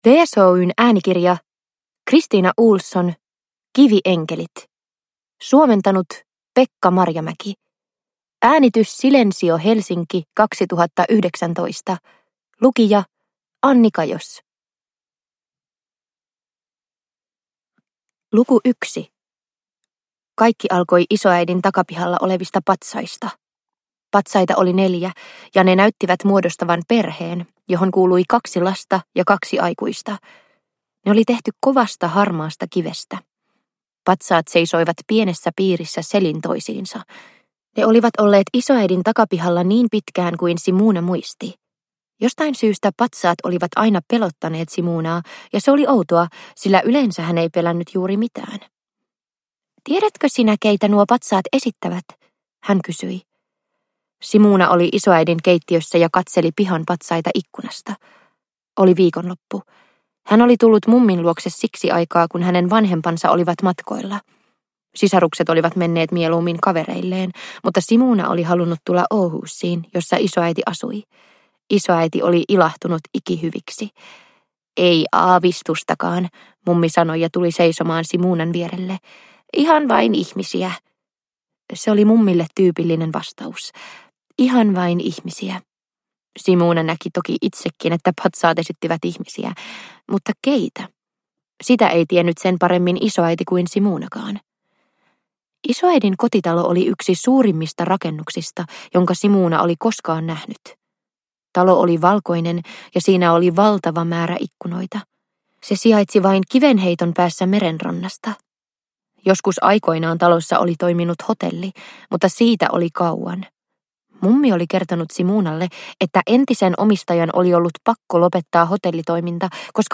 Kivienkelit – Ljudbok – Laddas ner